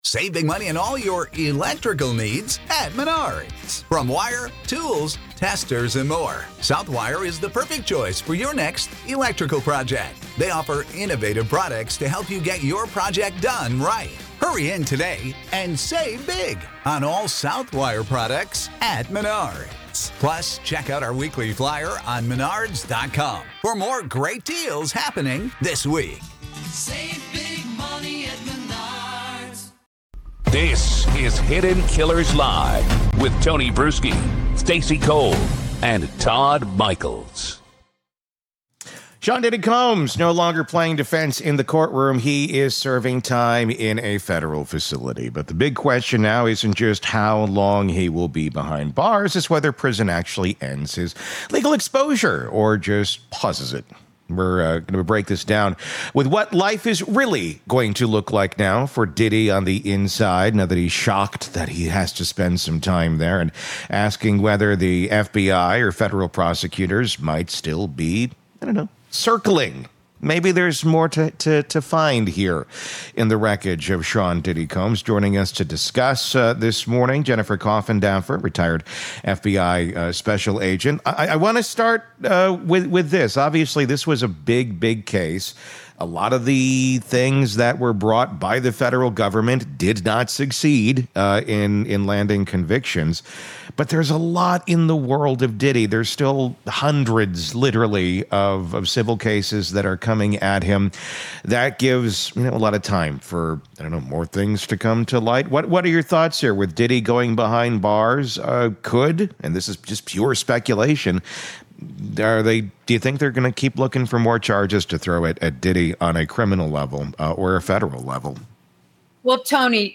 This interview lays bare the next chapter—and how the federal government may still be flipping pages.